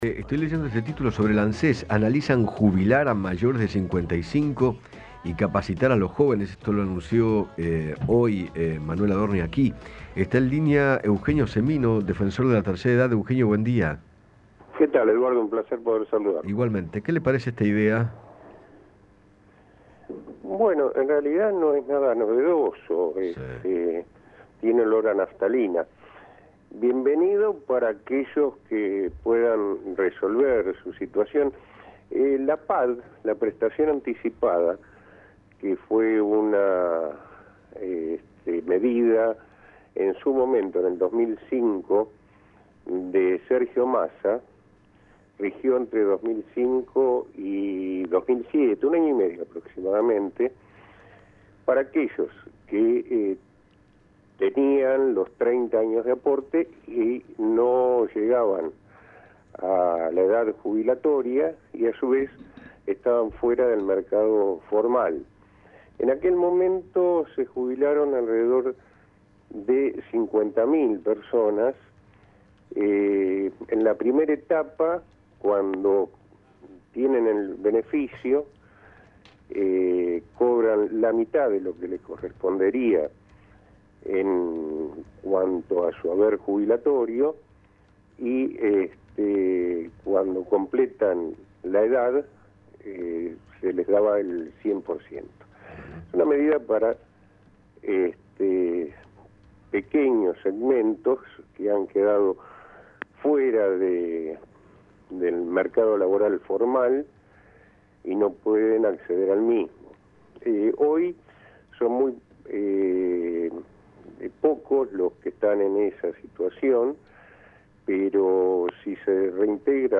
Eugenio Semino, Defensor del Pueblo de la Tercera Edad, conversó con Eduardo Feinmann sobre la posibilidad de jubilar a personas mayores de 55 años con el requisito de tener los 30 años de aportes y manifestó que “la medida no es novedosa”.